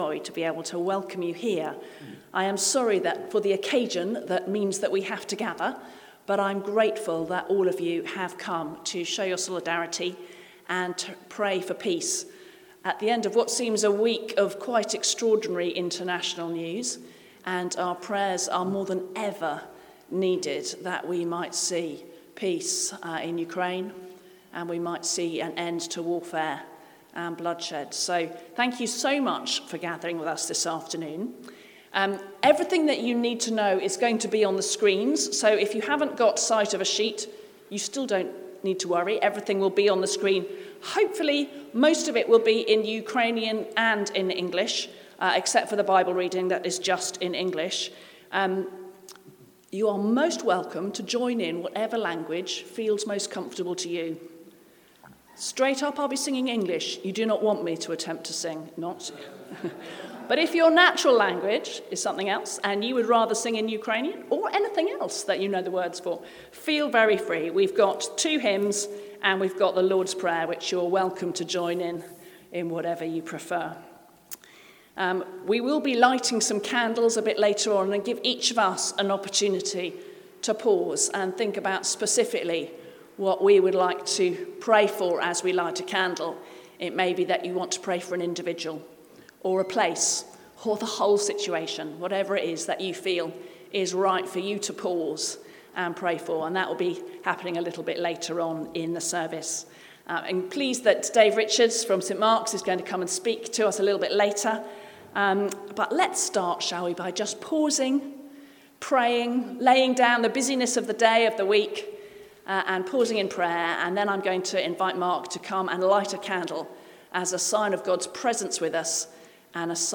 A service of peace for Ukraine was held at All Saints Church on Sunday, 23 February. The service was well attended with many of our guests from Ukraine taking part.